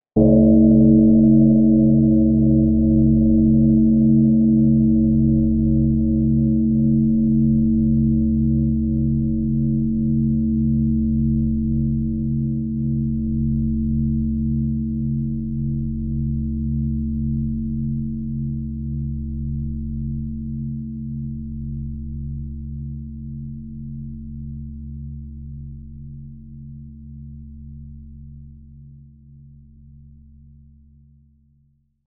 12月31日大晦日の夜、ゴーンと響く除夜の鐘の音。